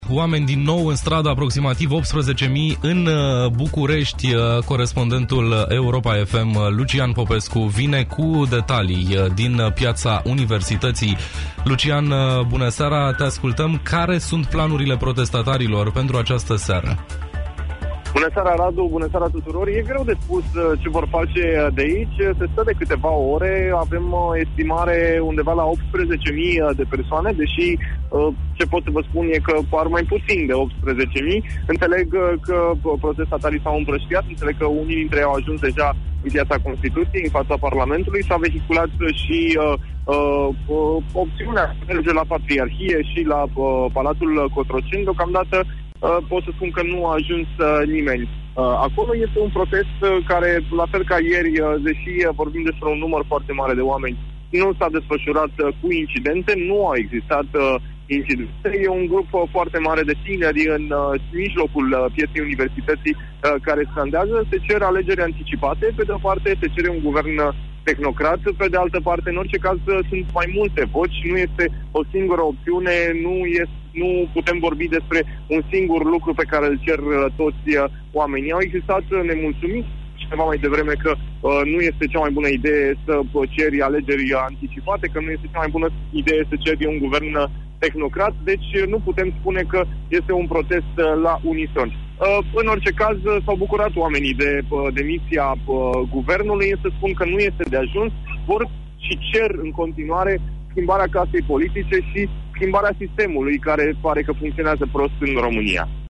Ediţie specială Ştirile Europa FM, în direct din mijlocul protestelor